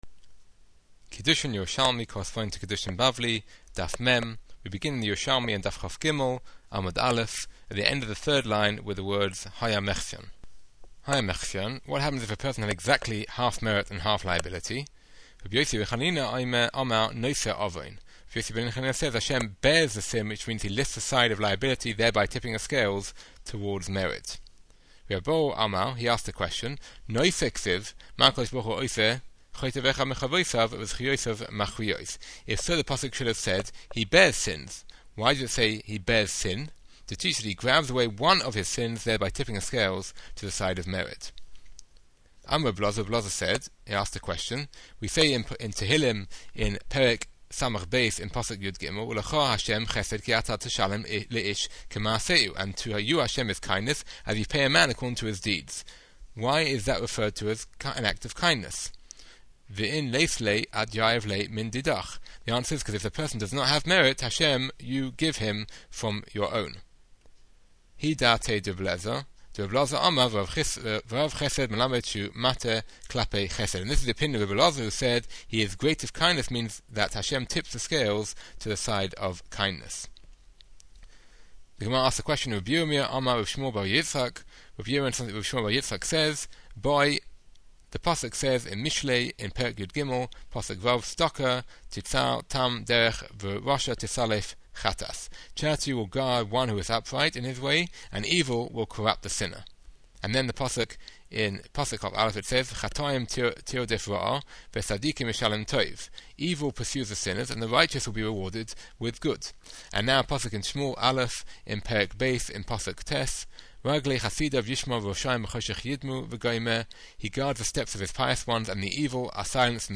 MP3's with detailed explanations of every Daf's Yerushalmi Matchup